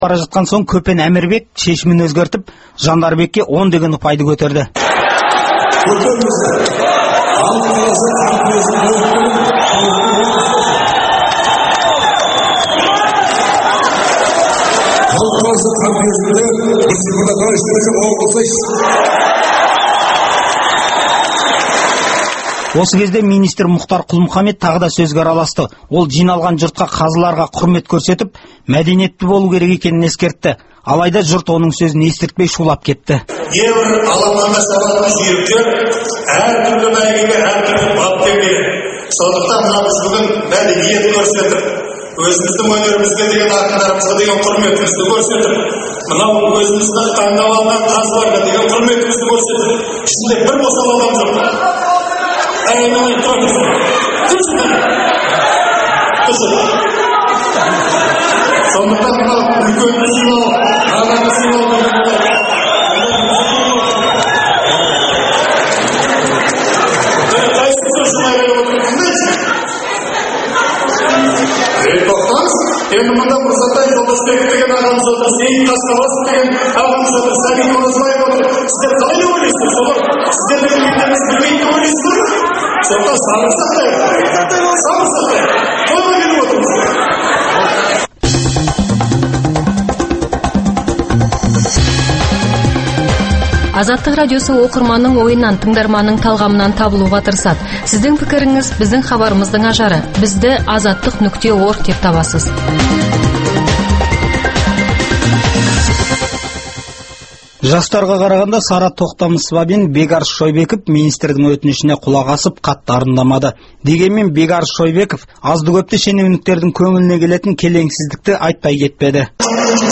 Тәуелсіздік күніне арналған тәуелді айтыс болып шыққан осы ақындар айтысынан арнайы дайындалған хабарымызды тыңдап отырсыздар.